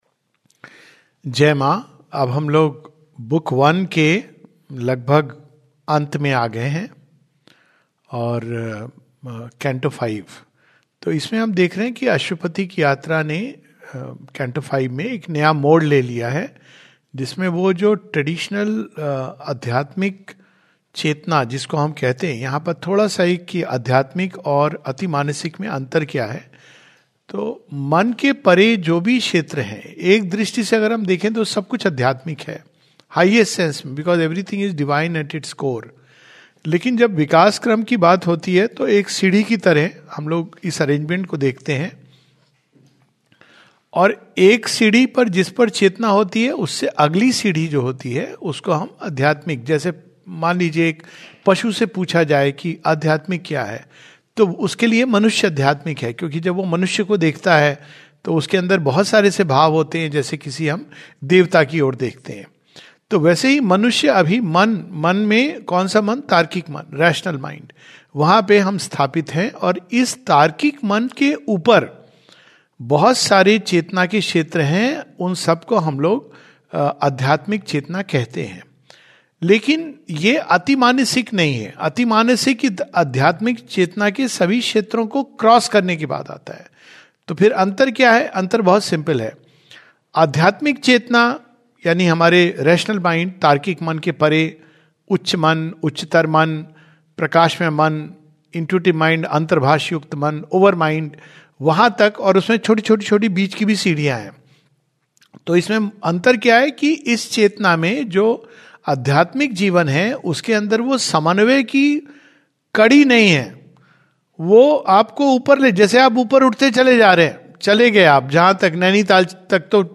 Savitri study class